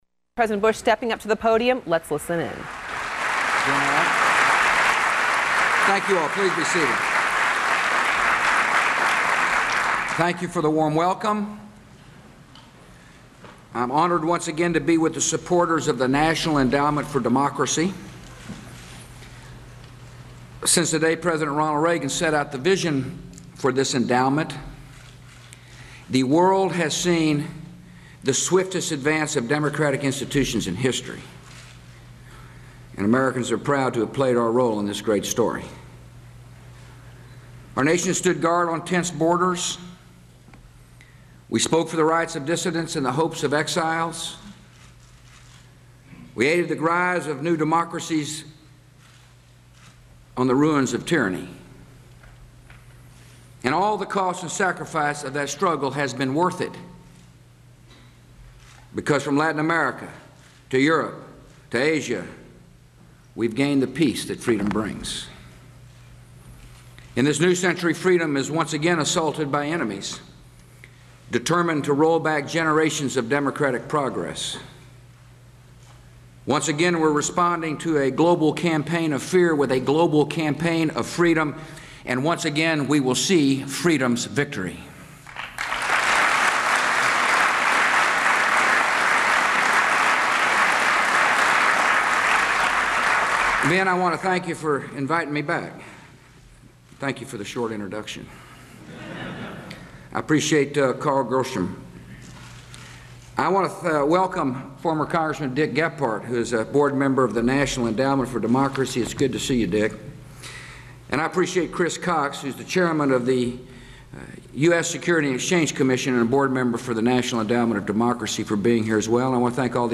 In a major foreign policy speech on world affairs since the September 11, 2001 attacks on the U.S., President Bush talks about efforts to combat global terrorism and the progress being made against al-Qaida. He also warns governments and agencies supporting terrorist groups to cease their activities and discusses military operations in Iraq. The speech is delivered at a meeting of the National Endowment for Democracy, to mark the 4th anniversary of the September 11 attacks.
Subjects Qaida (Organization) Iraq War (2003-2011) War on Terrorism (2001-2009) State-sponsored terrorism Iraq Material Type Sound recordings Language English Extent 00:39:54 Venue Note Broadcast on CNN, Oct. 6, 2005.